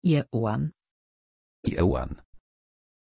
Pronunciación de Ieuan
pronunciation-ieuan-pl.mp3